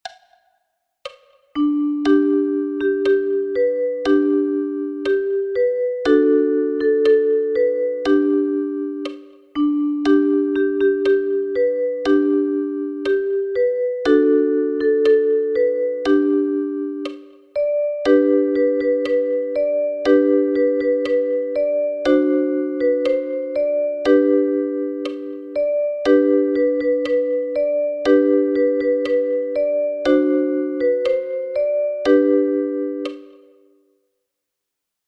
Ein Volkslied aus aus dem faszinierenden Peru.
Dies ist bis heute in der Musik der Anden hör- und spürbar und passt auch toll zur Ukulele 🙂